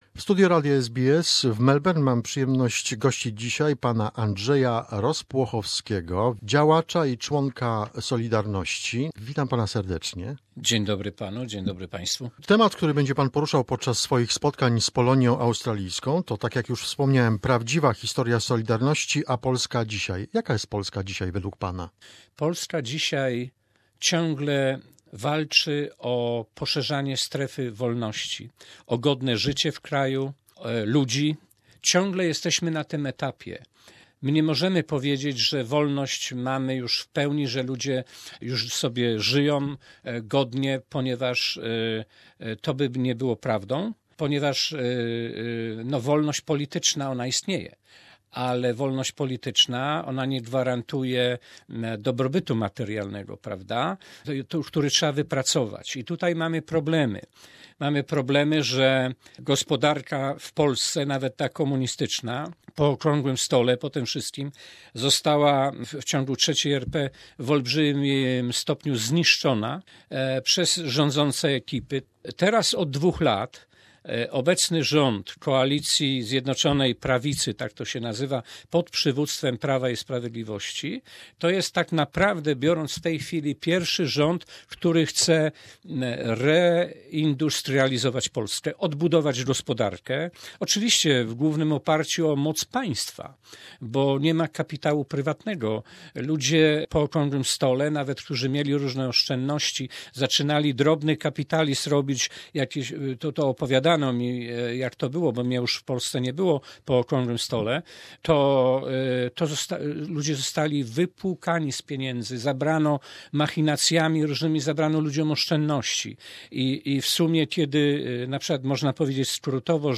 This is a part 3 of the interview.